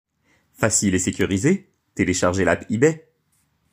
Voix off
Bandes-son
15 - 60 ans - Baryton